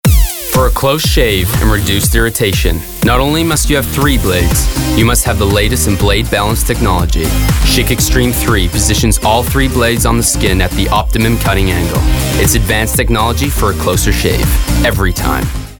He's friendly, approachable, and carries a natural charisma. He has a wide range and can bring you anything from vibrant and youthful to blokey and cool.
Location: Los Angeles, CA, USA Languages: english 123 Accents: English | New Zealand standard us Voice Filters: VOICEOVER GENRE commercial gaming NARRATION FILTERS bloke character comedic cool dramatic friendly smooth trustworthy warm